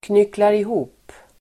Uttal: [knyklarih'o:p]